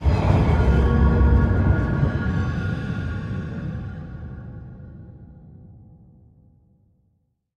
Minecraft Version Minecraft Version latest Latest Release | Latest Snapshot latest / assets / minecraft / sounds / ambient / cave / cave15.ogg Compare With Compare With Latest Release | Latest Snapshot
cave15.ogg